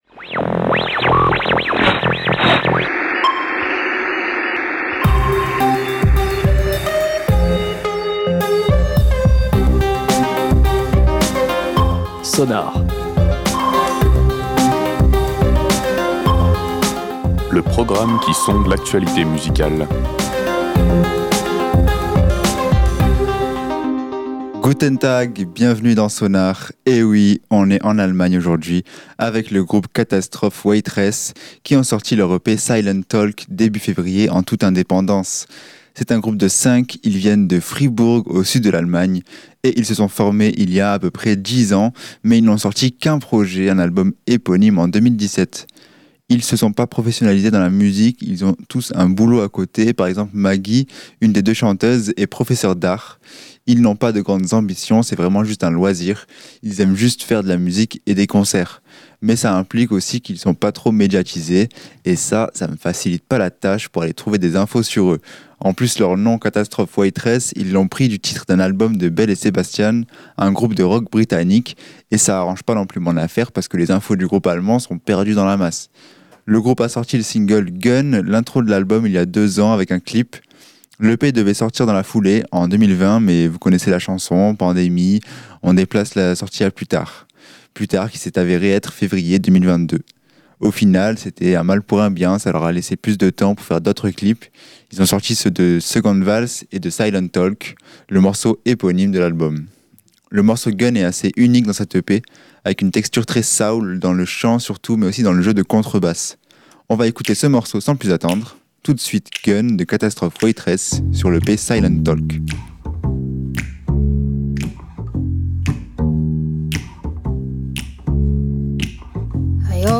Le morceau Gun est assez unique dans cet EP, avec une texture très soul, dans le chant surtout, mais aussi dans le jeu de contrebasse.
La musique de Catastrophe Waitress est un pur mélange de folk est de pop.
On retrouve une Shruti Box, un instrument indien. On a aussi une petite touche allemande avec un glockenspiel, un instrument proche du xylophone.